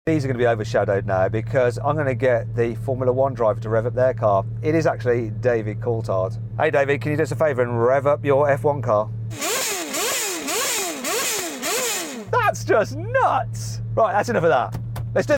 part 10 : F1 Car sound effects free download
David Coulthard Revs F1 Car_ Epic Engine Sound!